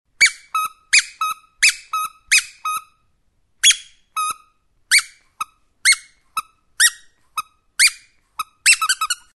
Звук пищащей игрушки при нажатии